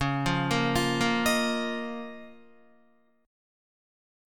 Db6add9 chord